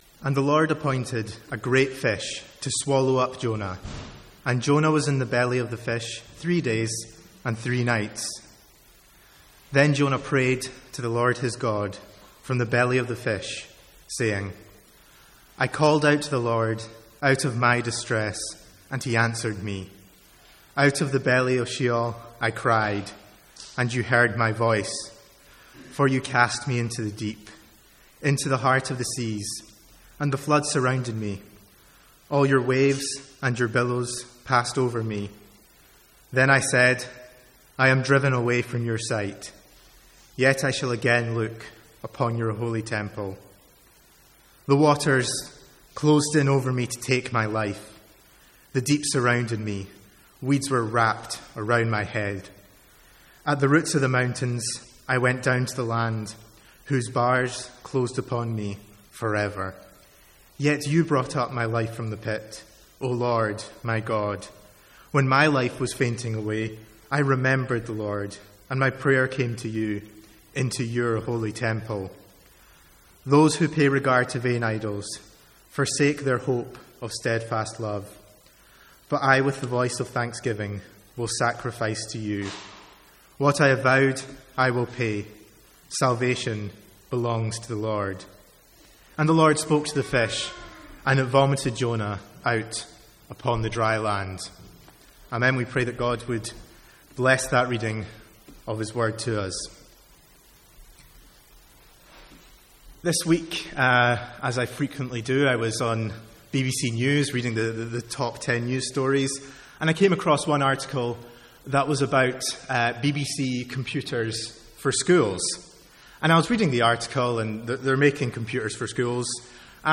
Sermons | St Andrews Free Church
From the Sunday evening series in Jonah.